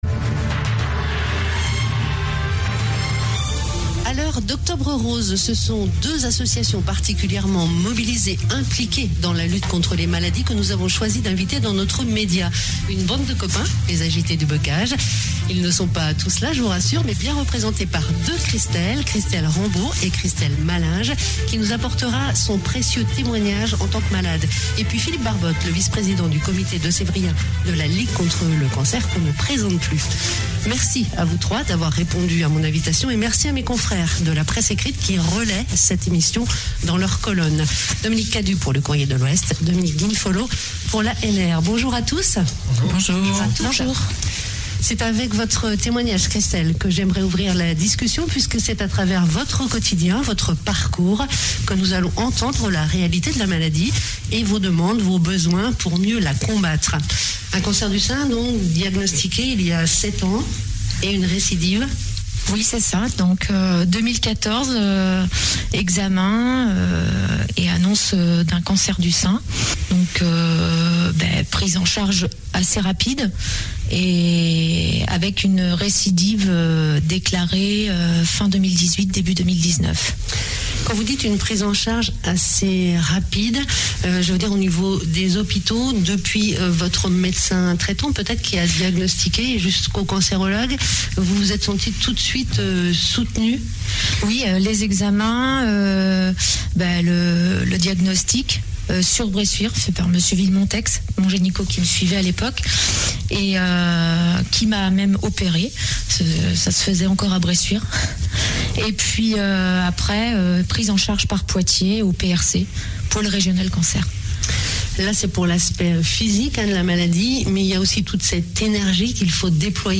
magazine de société 5 octobre 2021